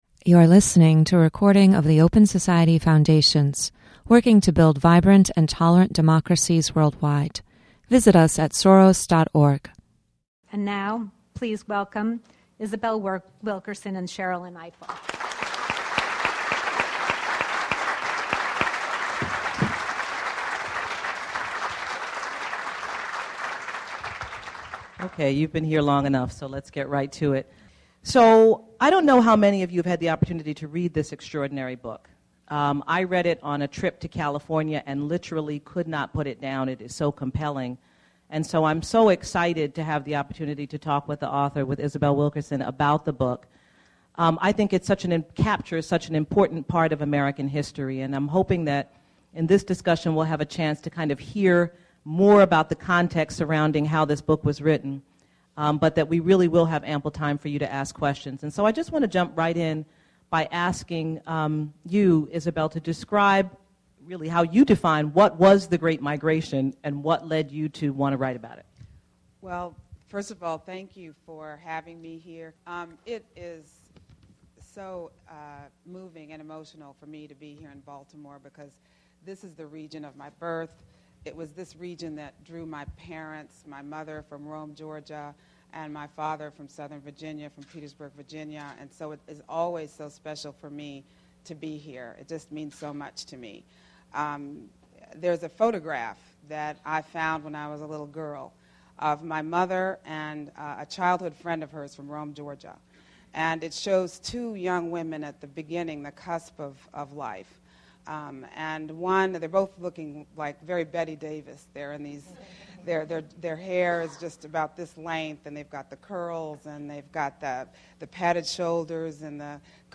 Join us for a discussion with Isabel Wilkerson, author of The Warmth of Other Suns: The Epic Story of America s Great Migration, who will be interviewed by Sherrilyn Ifill, civil rights lawyer and OSI-Baltimore board member.